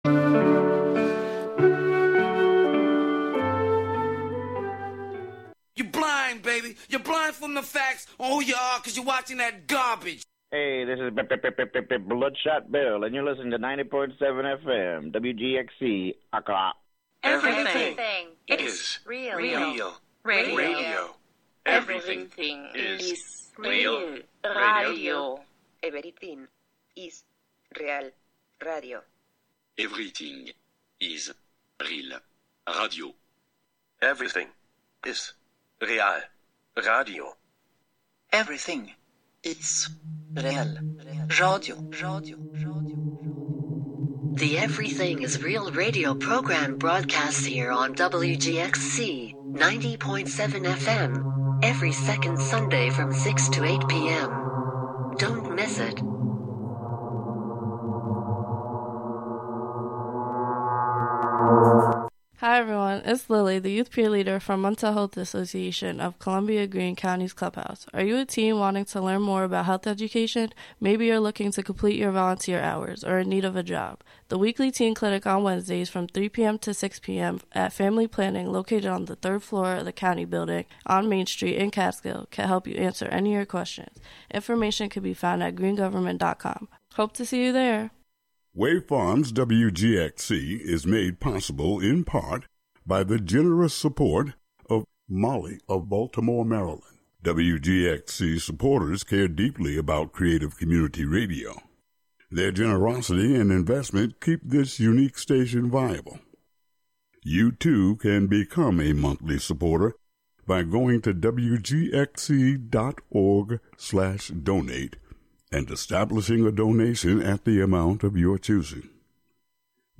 Pasqakuymi / Group of women of Socos performing harawi for Carnival celebration Chimaycha / Group of men performing chimaycha with charangos and guitars